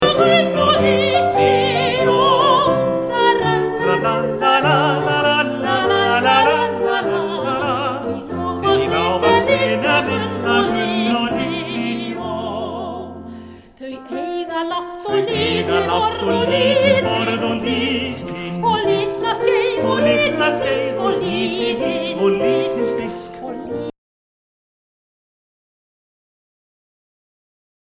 leikur á píanóið